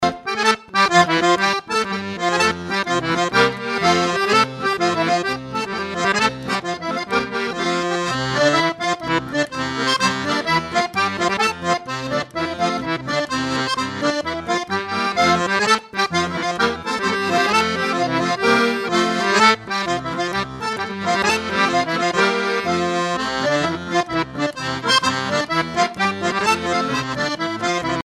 Couplets à danser
branle : courante, maraîchine
Groupe folklorique
répertoire du groupe Coueff's et Chapias en spectacle